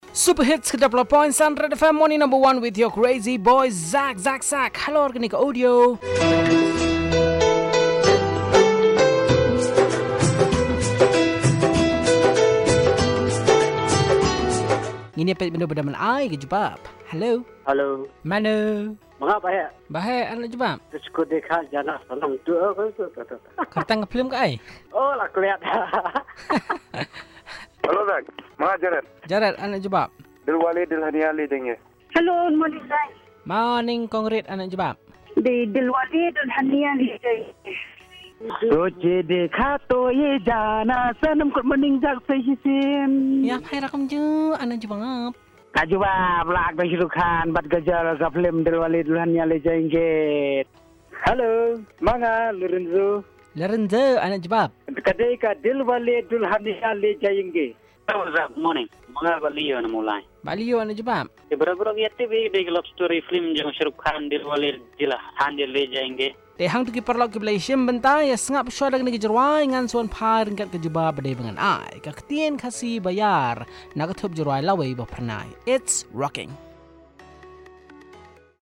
Callers